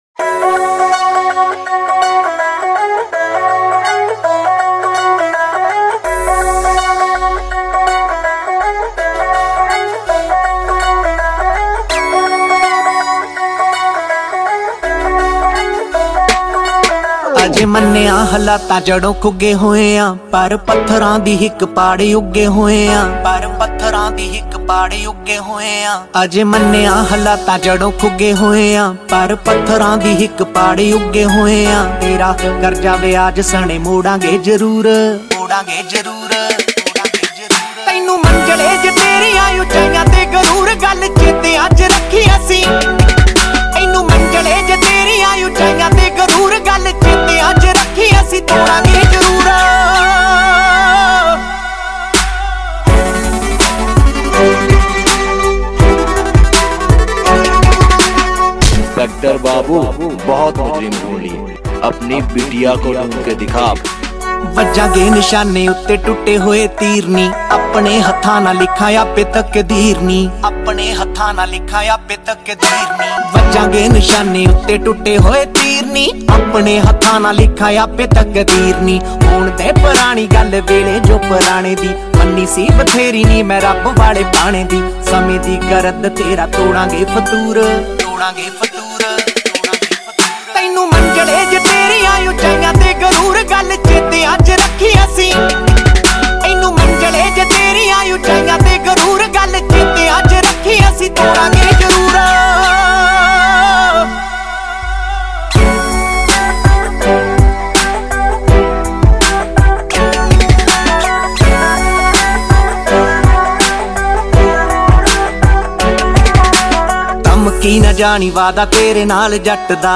Genre Latest Punjabi Songs